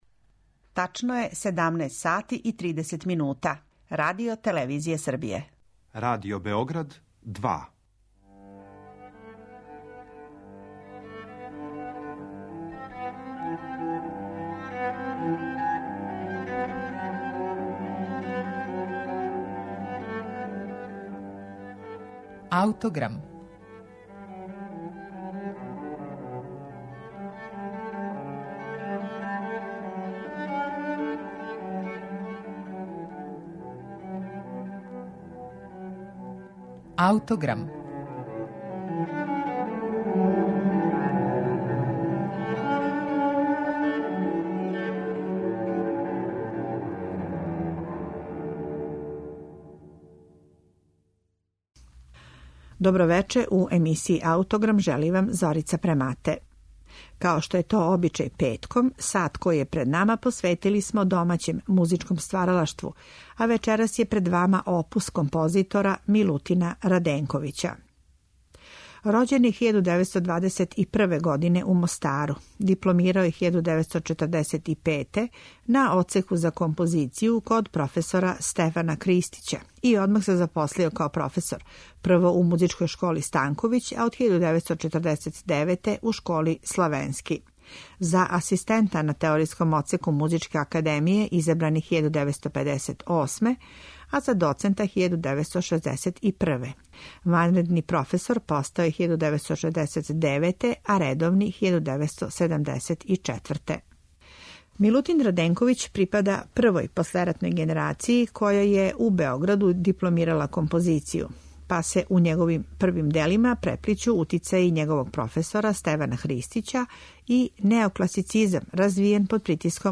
Емитоваћемо архивски снимак његовог циклуса од 12 клавирских прелида